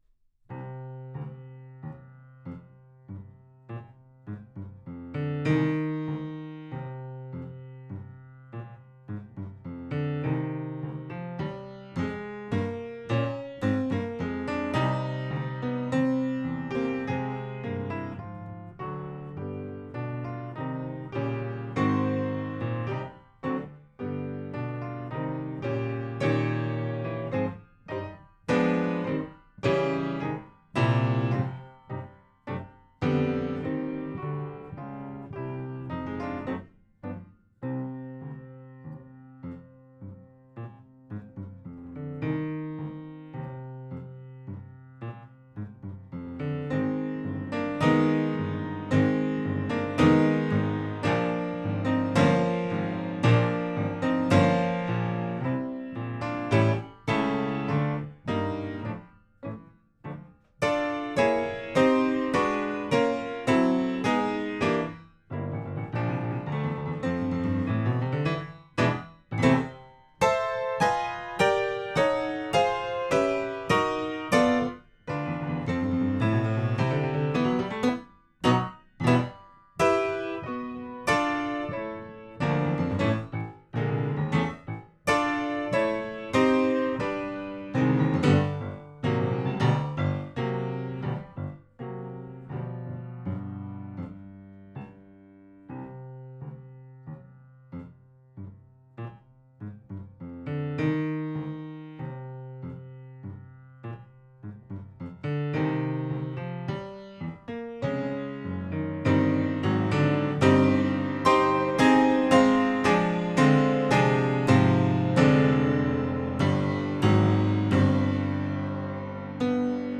Notes: for piano, orchestra